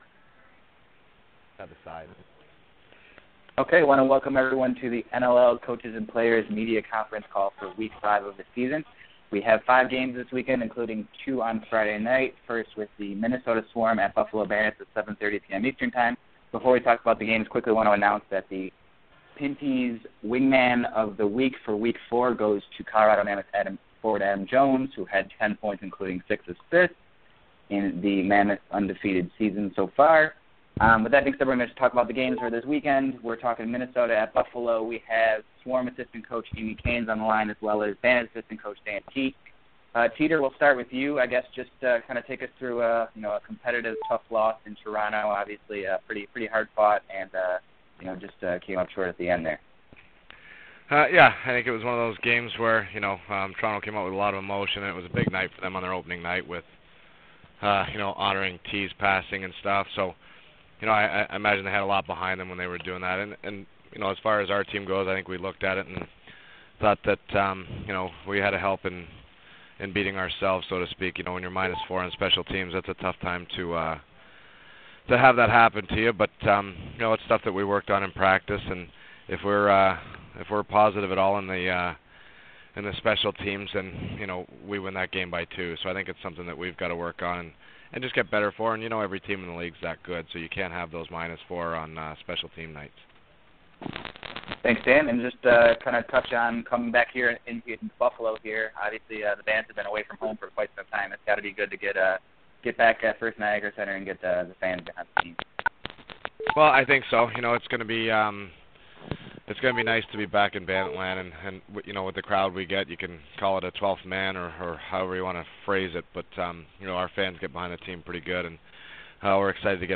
Members of the media talk with NLL coaches and GMs